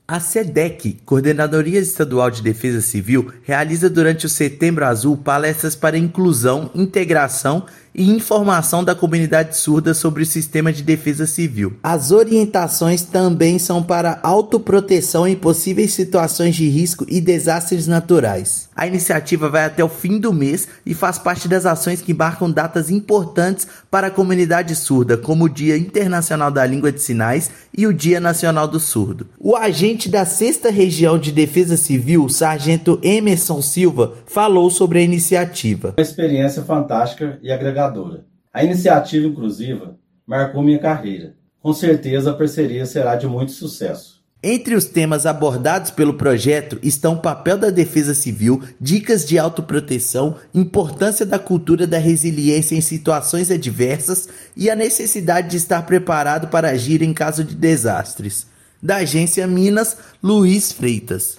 No Setembro Azul, órgão faz palestras em todo o estado para integrar a comunidade surda à cultura de prevenção e resiliência. Ouça matéria de rádio.